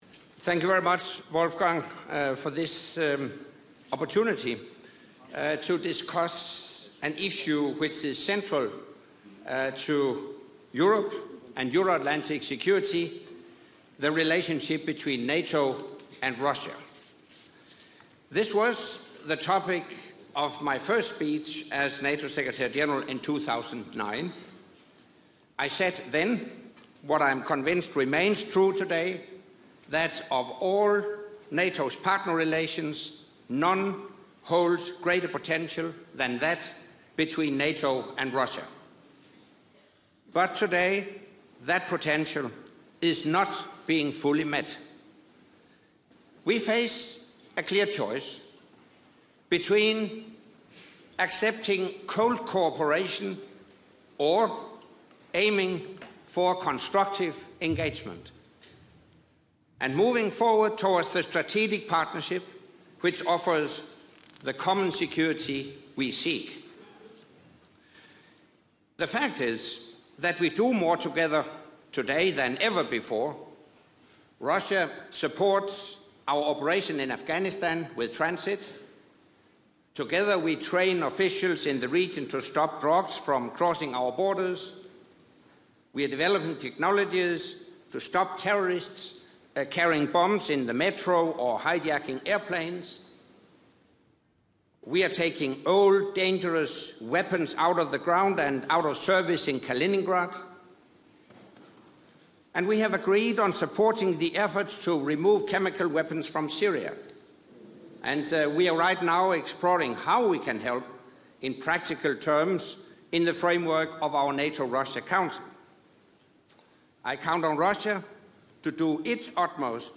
''NATO and Russia – Time to engage'' - Remarks by NATO Secretary General Anders Fogh Rasmussen at the Munich Security Conference
NATO Secretary General Anders Fogh Rasmussen addresses the 50th Munich Security Conference